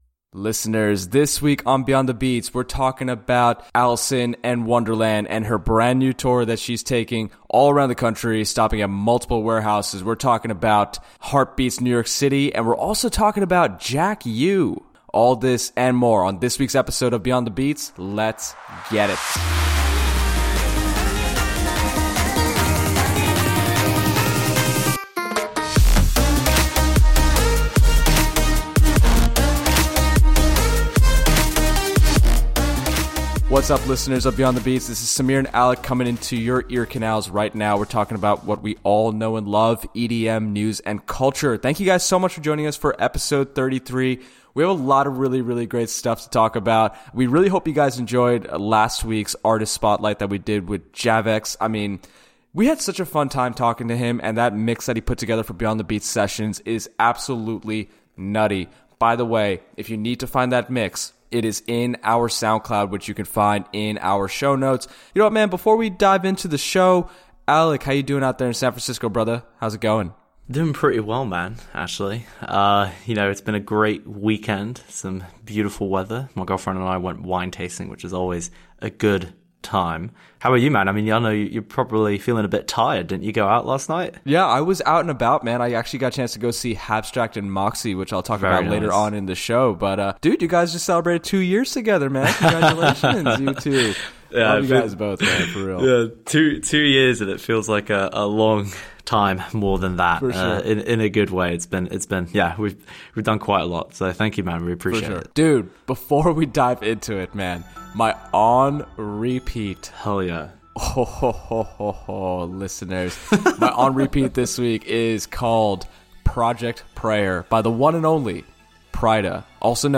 Jack U Return, H<3art Beats NYC Interview, Lost Lands, Pryda, It's Still Not Butter, and more | Ep. 33 ~ Beyond the Beats: EDM News and Culture Podcast